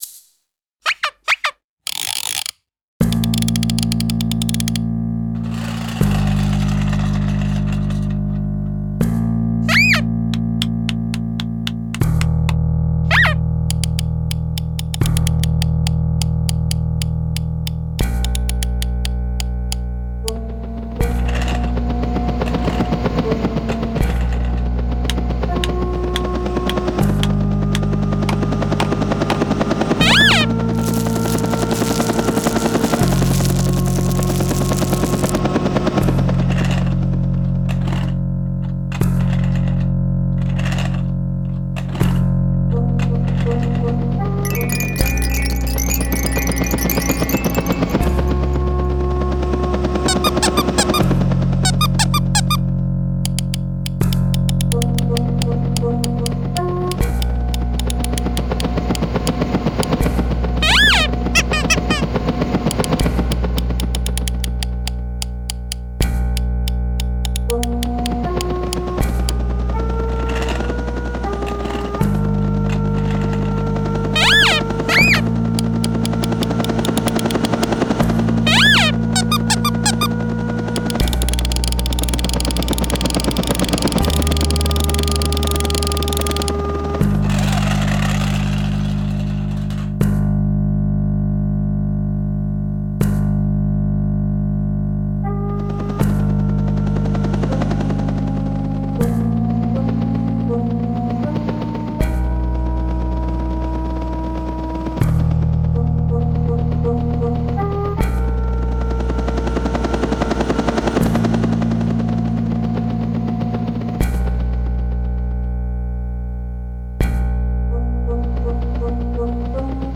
256SOUND consists out of a visual and AI generated auditory artwork, derived from the data in the genesis 256 ART pieces.